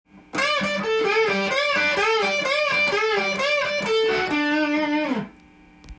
すべてのフレーズがDまたはD7で使えます。
②のフレーズは２弦と３弦のチョーキングを絡ませながら
ブルース系の教科書的なフレーズ。